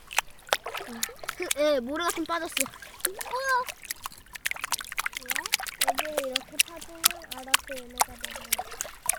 손장구.ogg